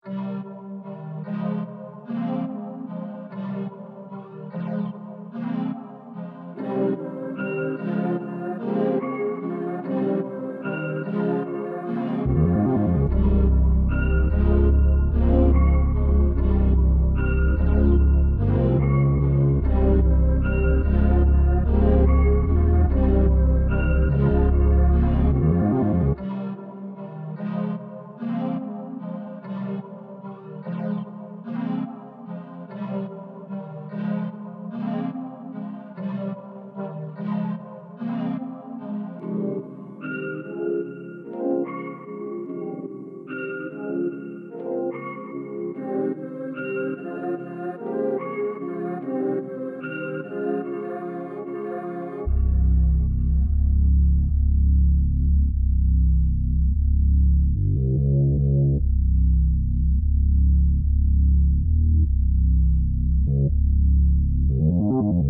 EN - Aqua (147 BPM).wav